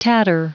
Prononciation du mot tatter en anglais (fichier audio)
Prononciation du mot : tatter